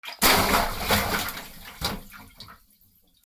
/ M｜他分類 / L30 ｜水音-その他
水に落とす 水音
『ザブーン』